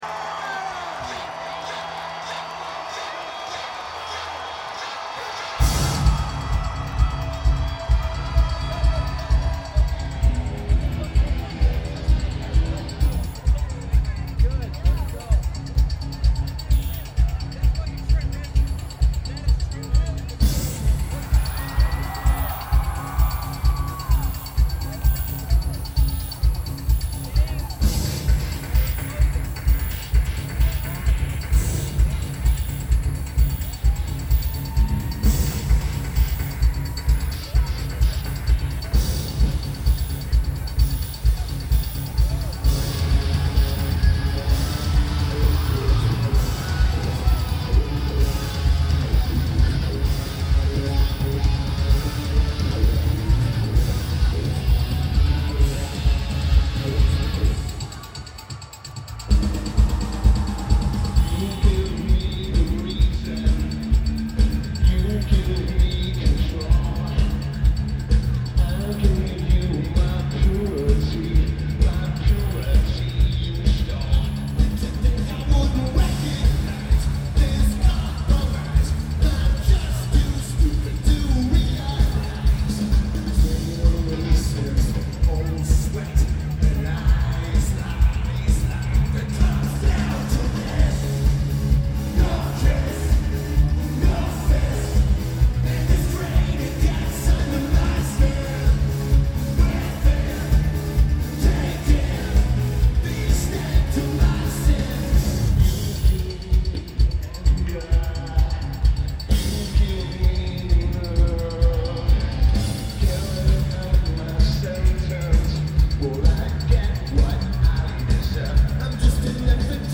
Ford Amphitheatre
Lineage: Audio - AUD (DPA 4061s + MMA-6000 + Edirol R-09)